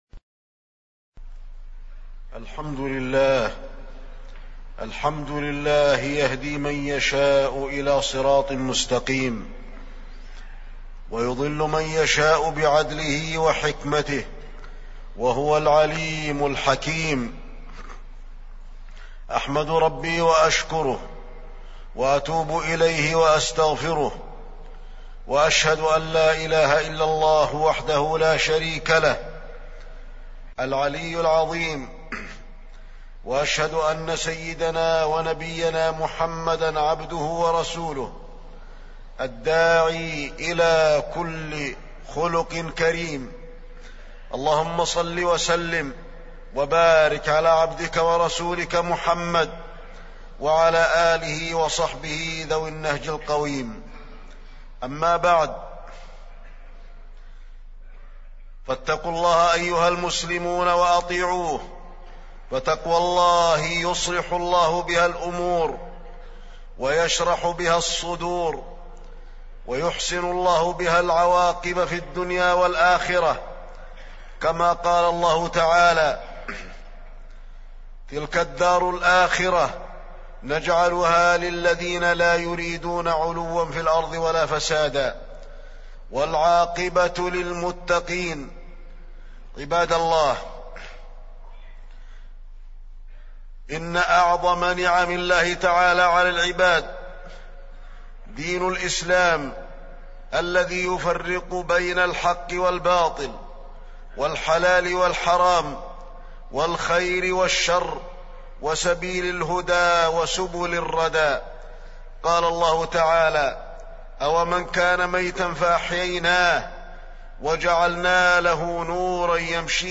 تاريخ النشر ٢٩ صفر ١٤٢٦ هـ المكان: المسجد النبوي الشيخ: فضيلة الشيخ د. علي بن عبدالرحمن الحذيفي فضيلة الشيخ د. علي بن عبدالرحمن الحذيفي البدع وإجتنابها